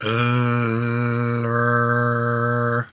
L and R sounds alone
l-r-alternate-one.mp3